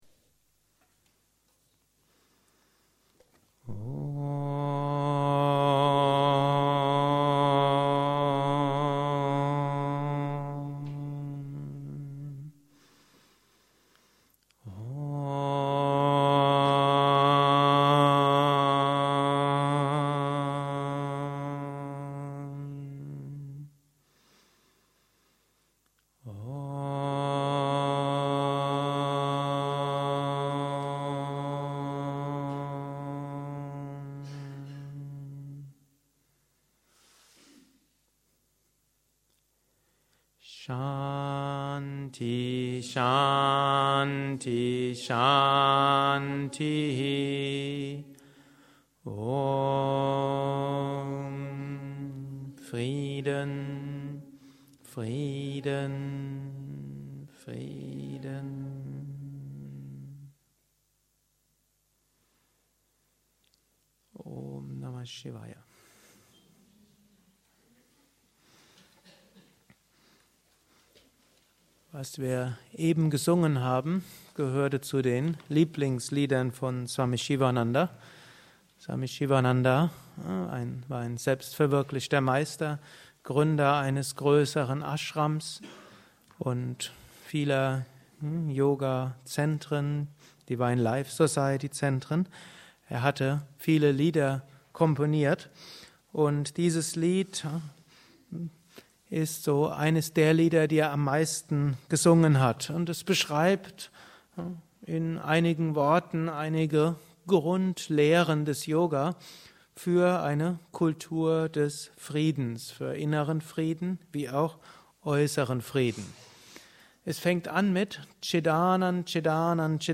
Vortrag auf dem Yoga Kongress im November 2007: Diene, Liebe, Gib.